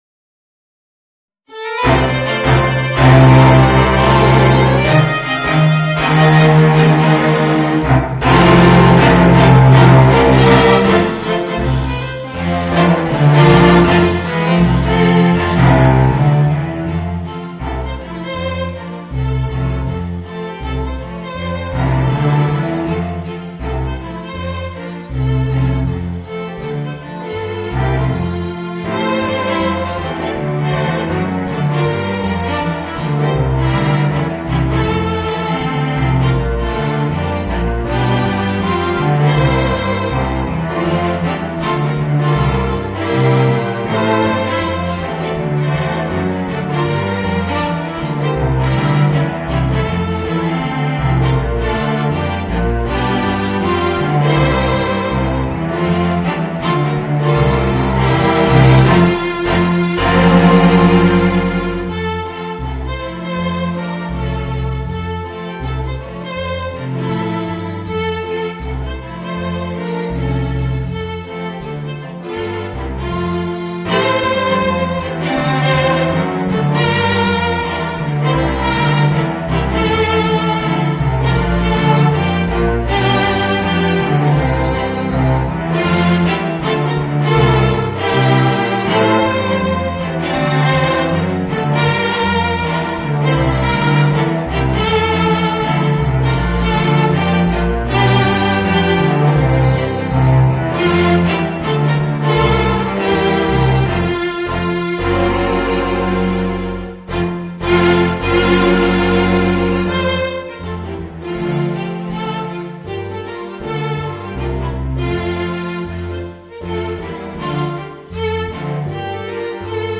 Voicing: String Quintet